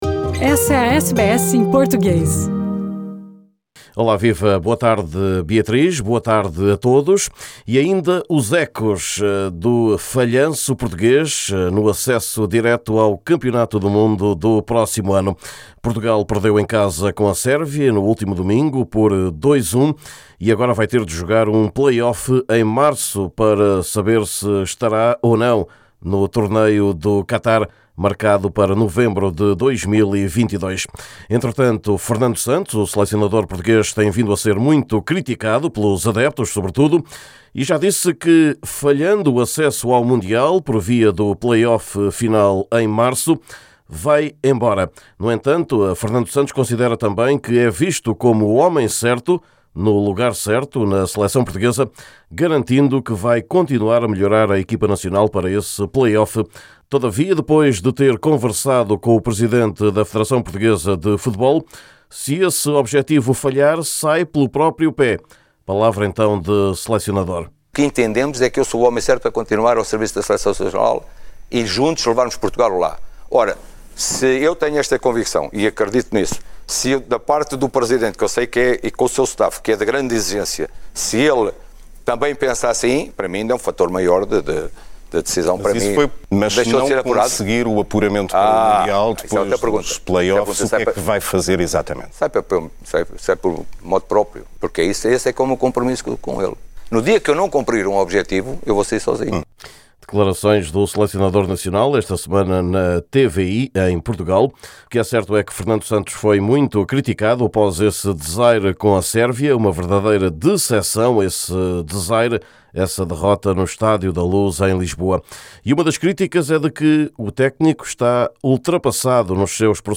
Neste boletim, para ouvir também, o mercado ou as últimas da saída para a estrada da mítica meia-maratona de Lisboa, após longa interrupção devido à pandemia.